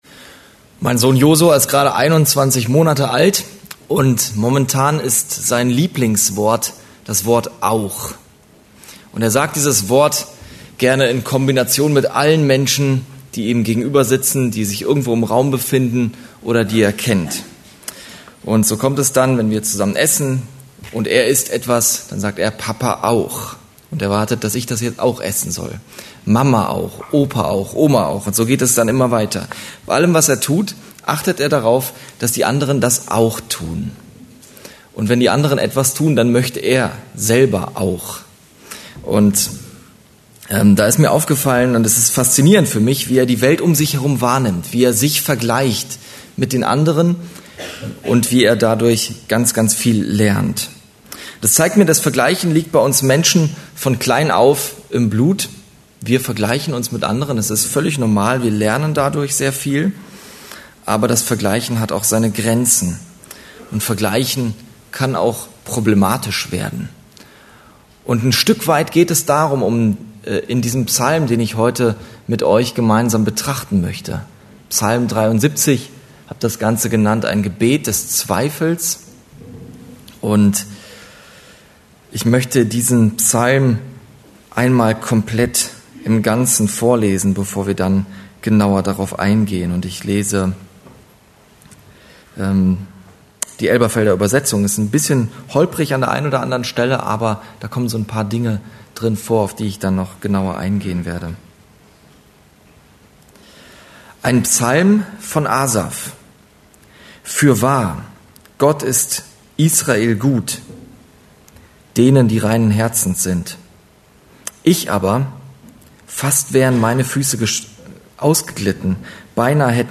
Serie: Einzelpredigten